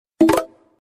Sound Buttons: Sound Buttons View : Grindr Notification